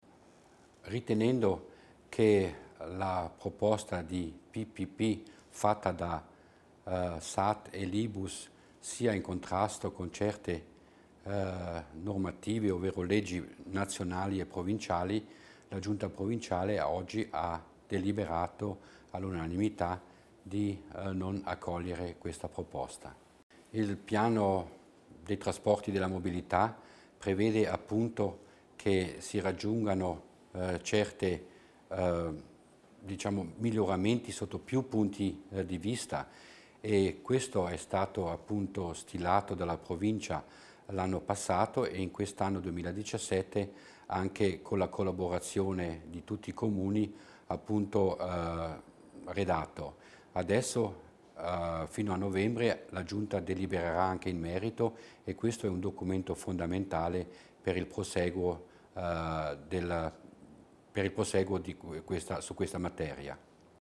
L'Assessore Florian Mussner spiega le motivazione del rifiuto della proposta SAD-LiBUS